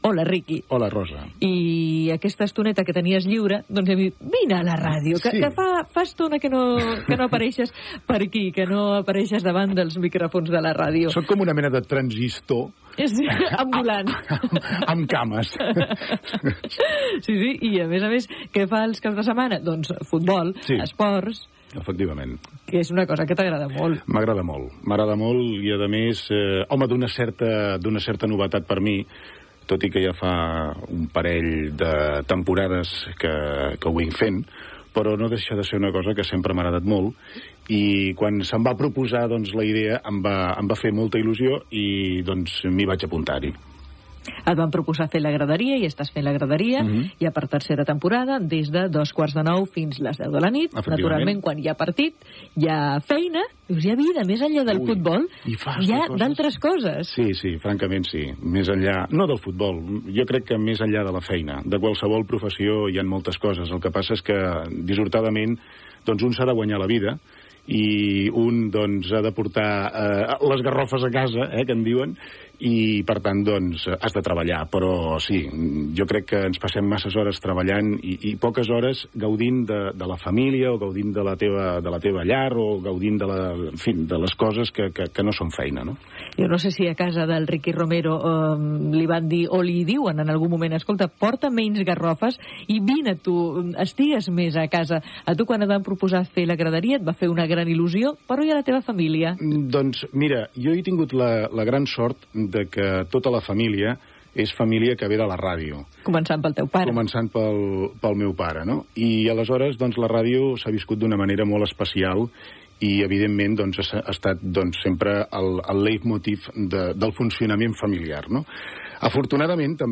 Entrevista
Divulgació
FM